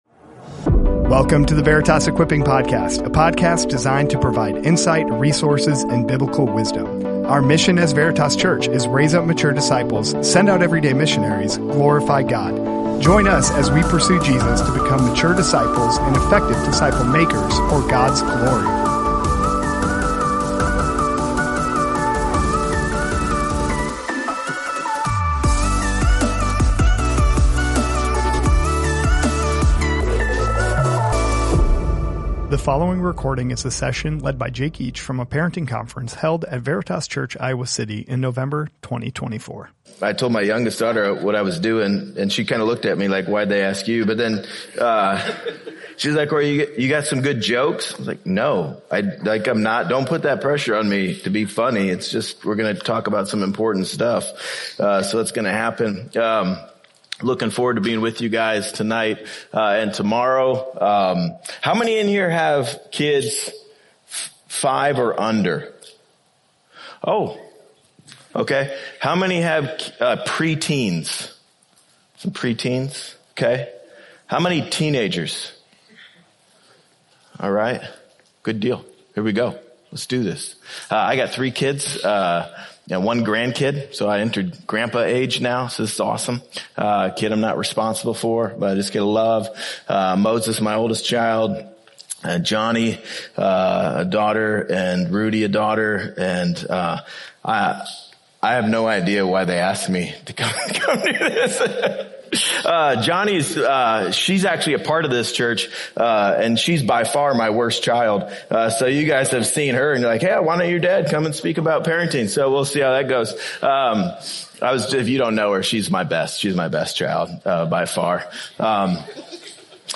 from a Parenting Conference at Veritas Church Iowa City in November 2024